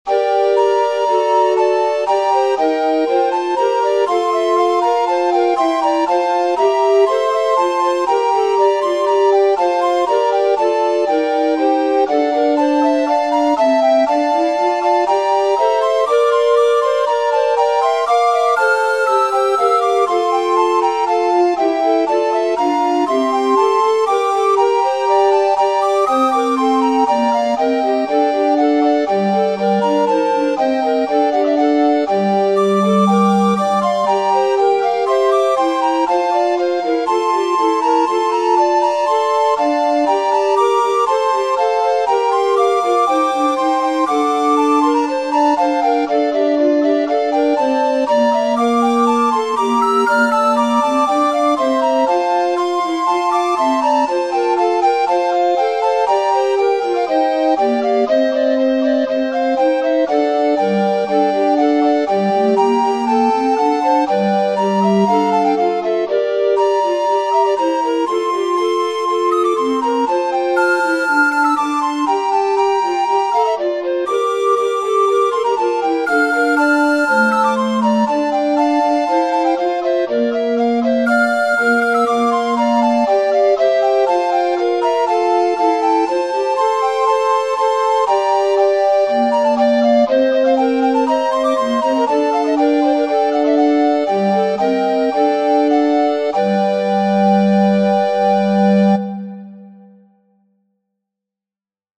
Small Passamezzo for recorder consort
The technique i used is pretty straightfoward, i used the passamezzo bass notes as pillars and in between free counterpoint. That way it doesnt sound so monotonous. And it allows for spicy imitations!